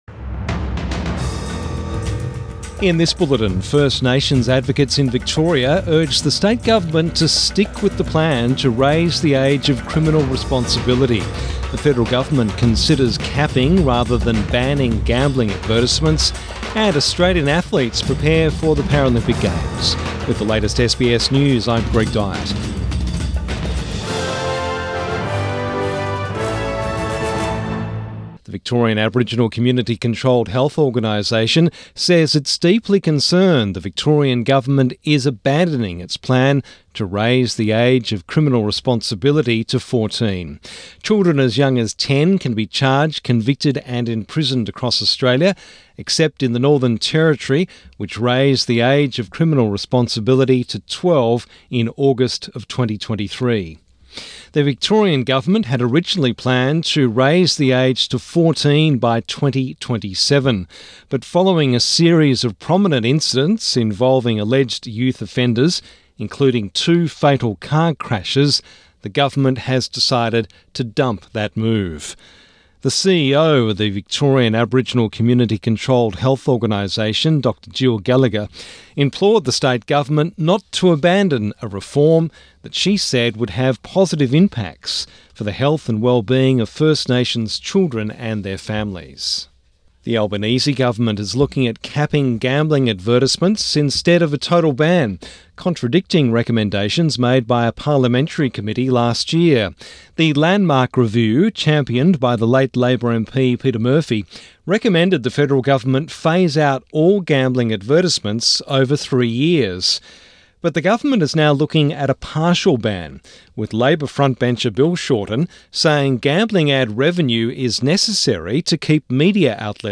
Midday News Bulletin 13 August 2024